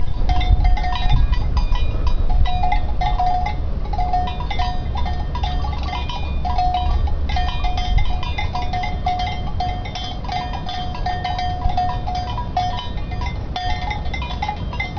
What I think will especially stay a lively memory of this tour are the sounds of cow and sheep bells coming from the mountain meadows, accompanying us almost everywhere while geocaching, providing for a very special atmosphere.
Listen to it and imagine yourself hiking and geocaching in an alpine landscape!
cows.wav